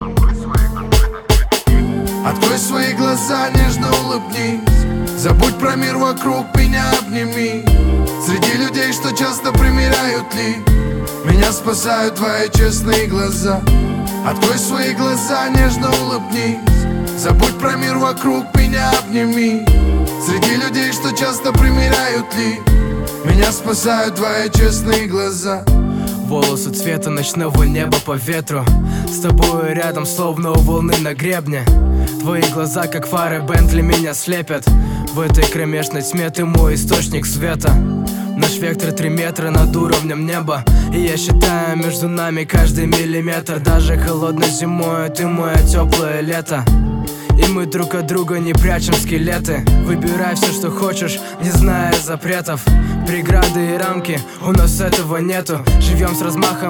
лирика
Хип-хоп
русский рэп
красивая мелодия